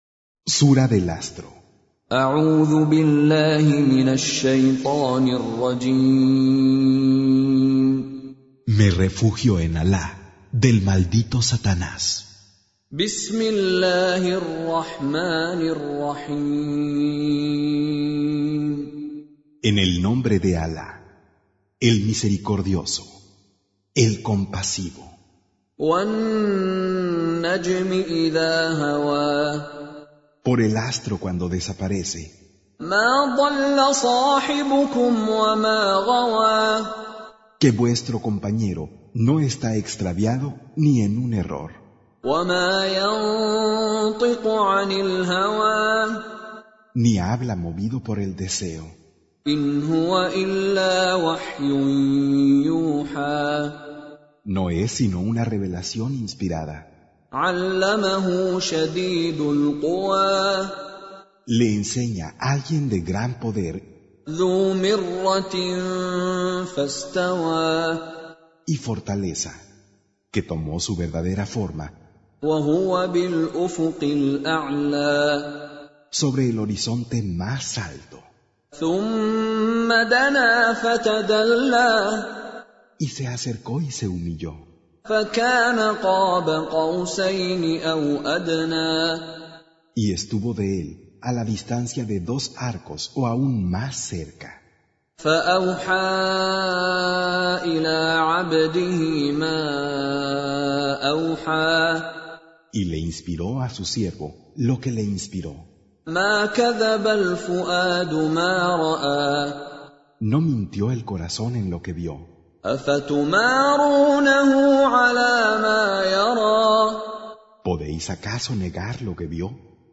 Surah Sequence تتابع السورة Download Surah حمّل السورة Reciting Mutarjamah Translation Audio for 53. Surah An-Najm سورة النجم N.B *Surah Includes Al-Basmalah Reciters Sequents تتابع التلاوات Reciters Repeats تكرار التلاوات